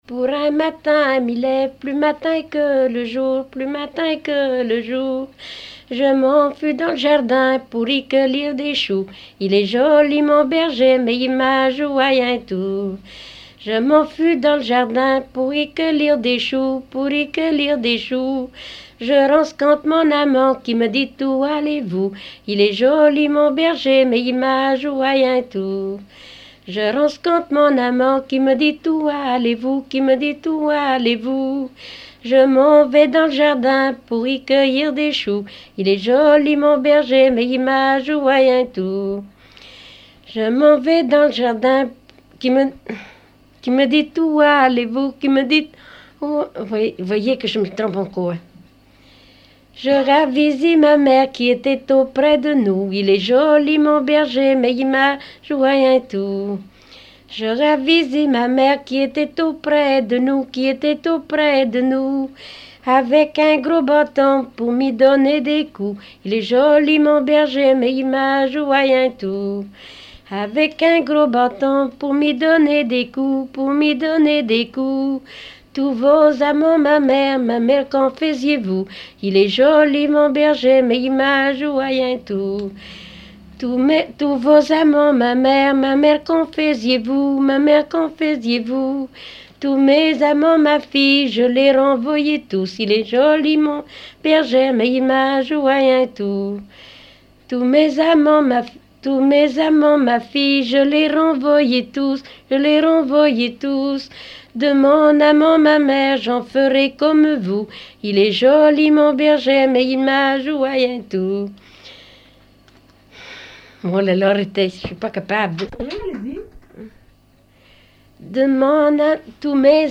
Dialogue mère-fille
danse : ronde
Pièce musicale inédite